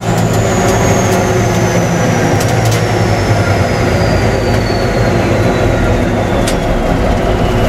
throttle_off.wav